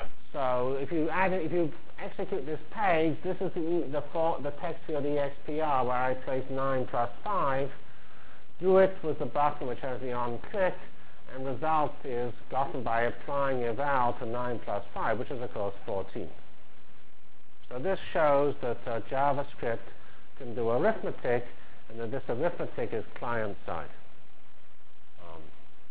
Full HTML for GLOBAL Feb 12 Delivered Lecture for Course CPS616 -- Basic JavaScript Functionalities and Examples